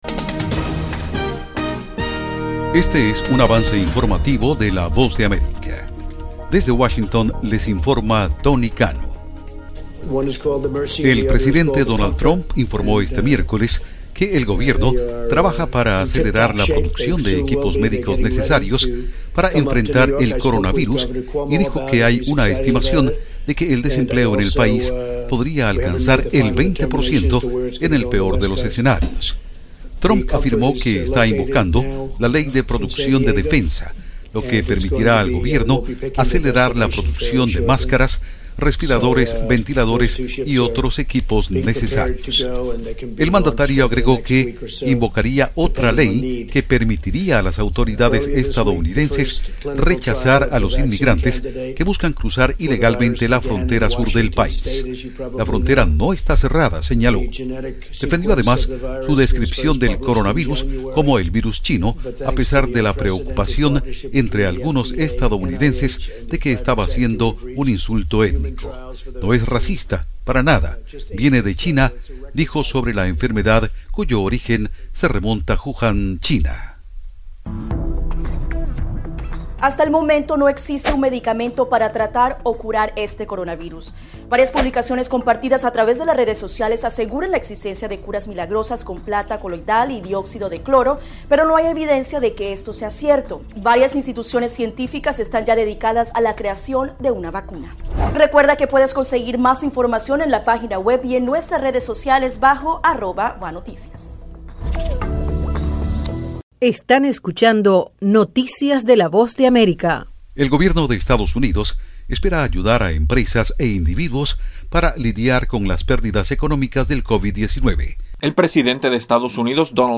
VOA: Avance Informativo 3:00 PM